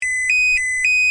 AJ_Warning_2.ogg